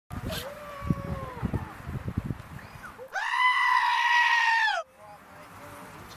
The Screaming Goat